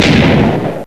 explode.ogg